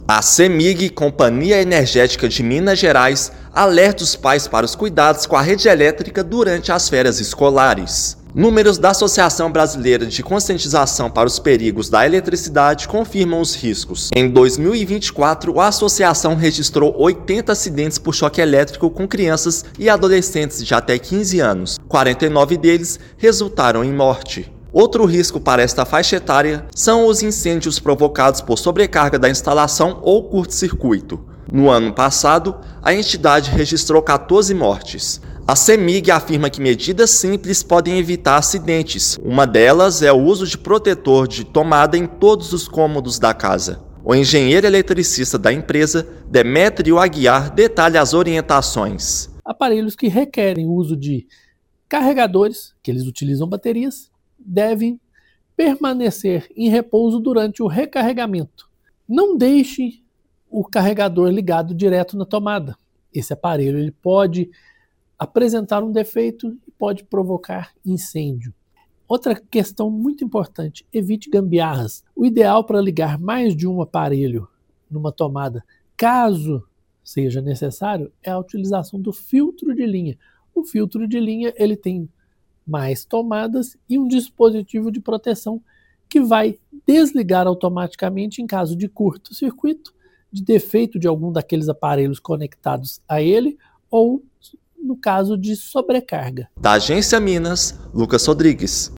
Empresa orienta pais e responsáveis sobre medidas simples que evitam acidentes com eletricidade entre crianças e adolescentes. Ouça matéria de rádio.